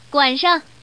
Index of /fujian_pw_test/update/3227/res/sfx/common_woman/